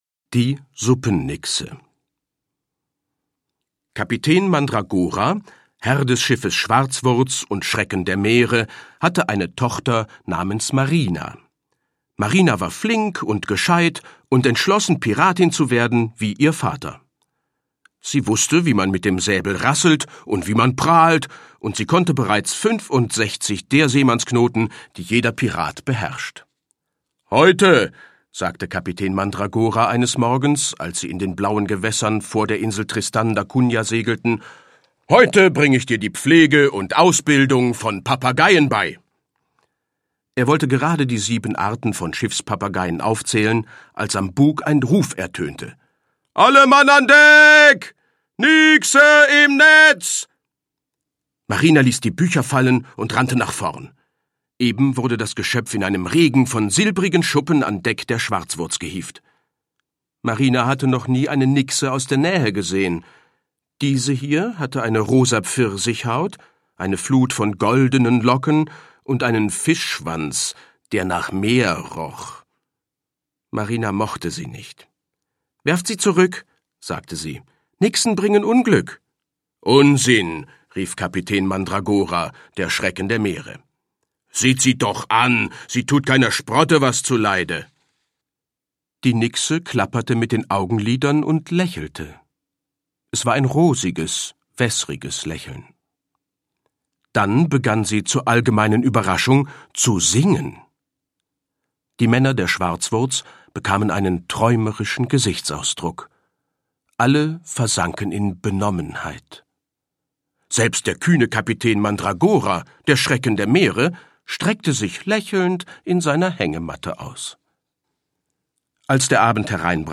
Seeräubergeschichten und salzige Lieder